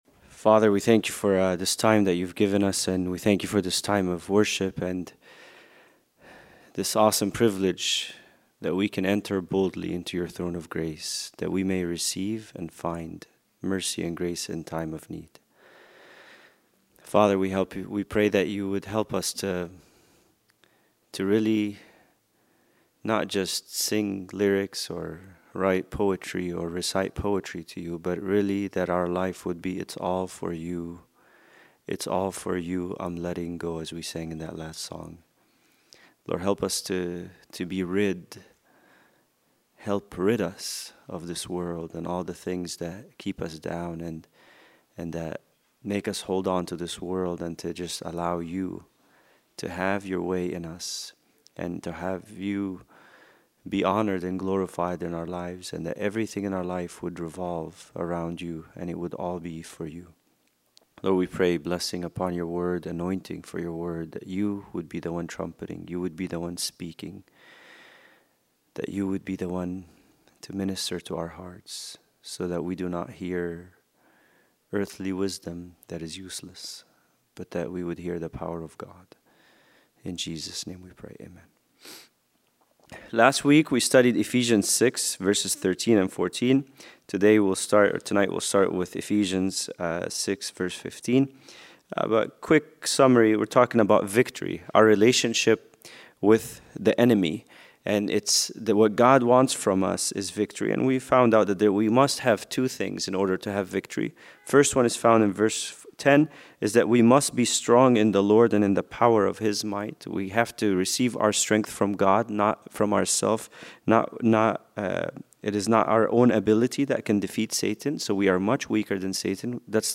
Bible Study: Ephesians 6:15-17